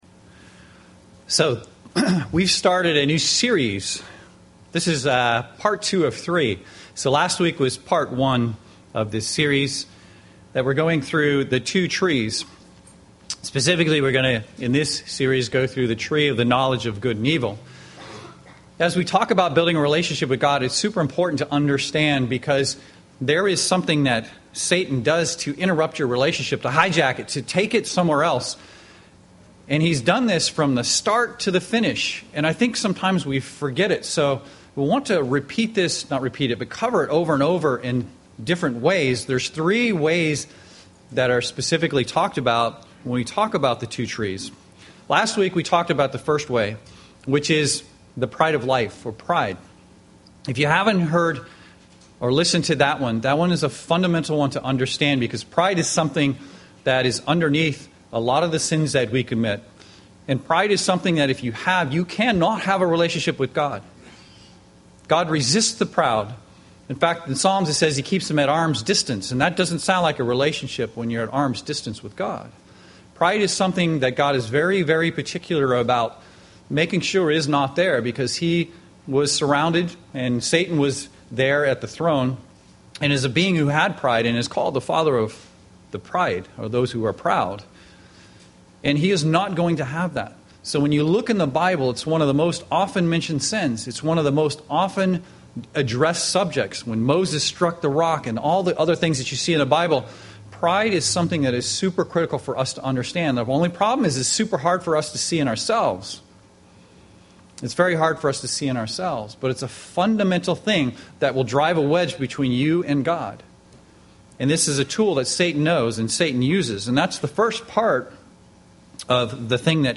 Given in Seattle, WA
UCG Sermon sin lust Studying the bible?